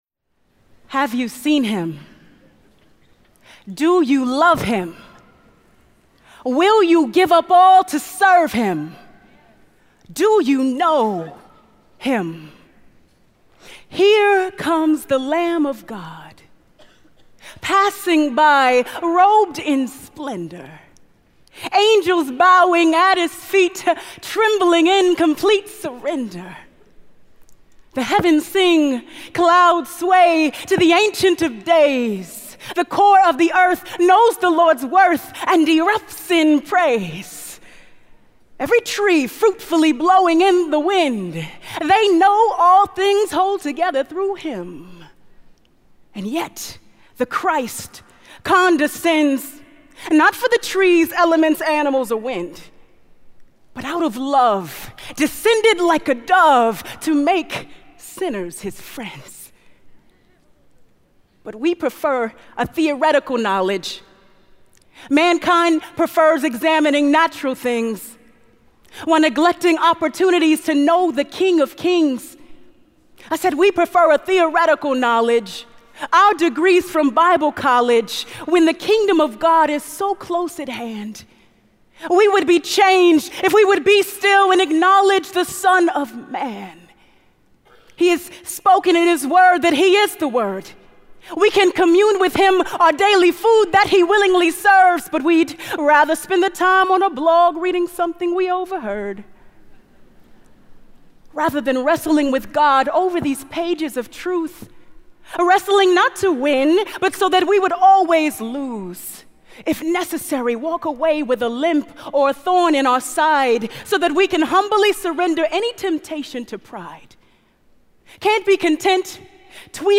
(Spoken Word Poetry) | True Woman '14 | Events | Revive Our Hearts